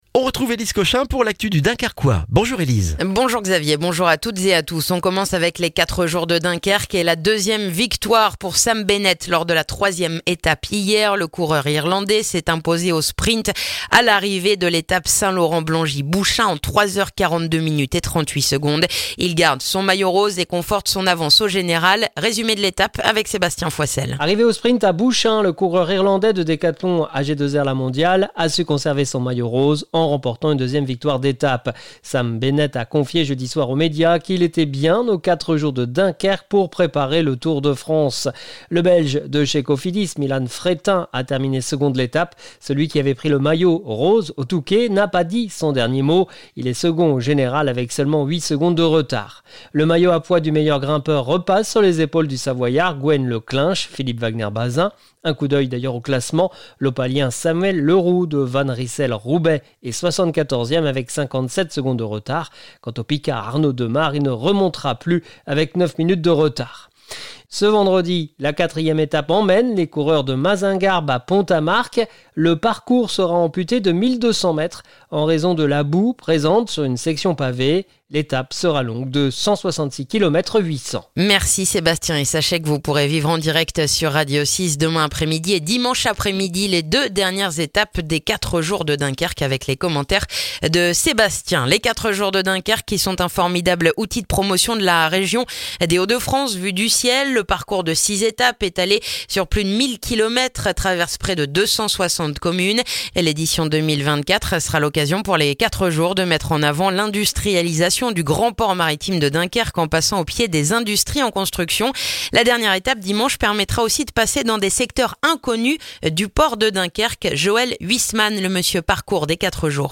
Le journal du vendredi 17 mai dans le dunkerquois